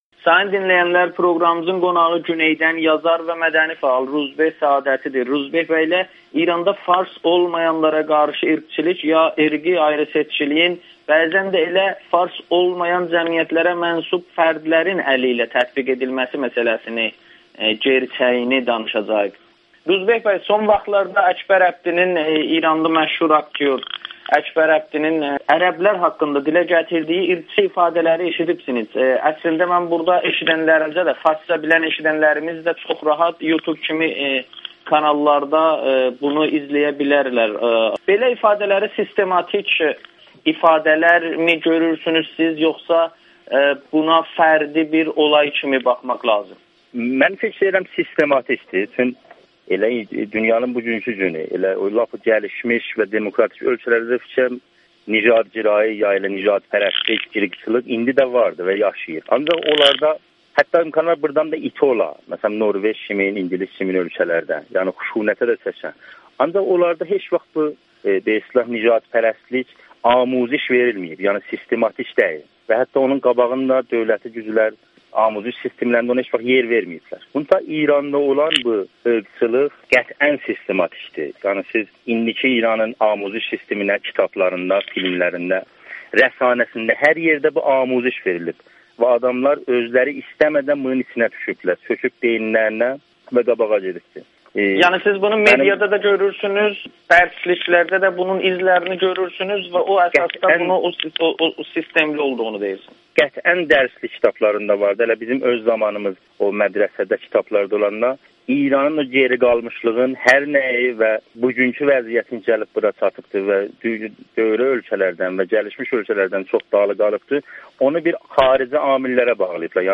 İranda irqçilik məktəblərdə öyrədilir [Audio-Müsahibə]